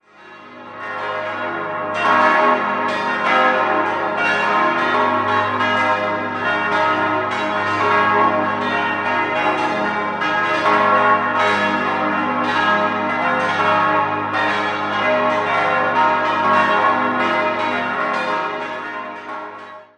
Architekt war August Hardegger. 6-stimmiges Geläut: as°-c'-es'-f'-as'-b' Die Glocken wurden 1890 von der Firma Rüetschi in Aarau gegossen.